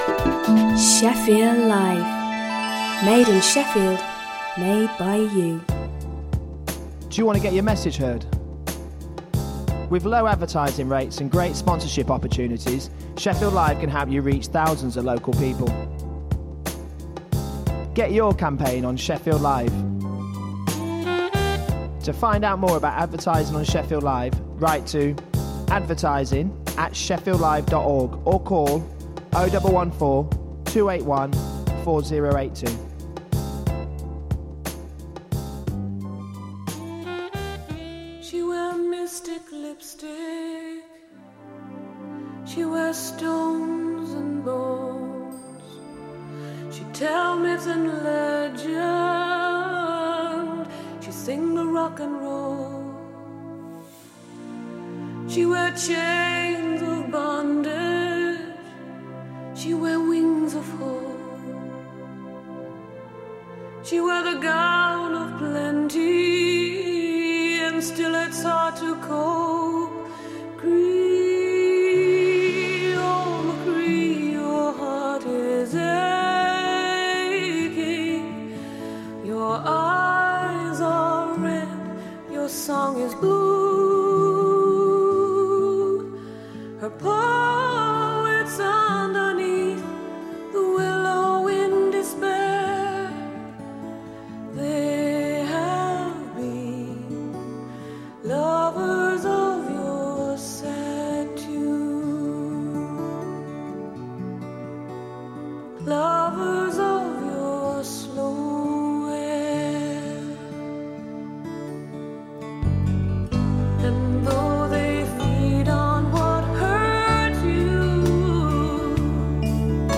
Shefffield Live presents…Aaj Ka Sabrang : A mix of different flavours of Asian music from the sub-continent and chat.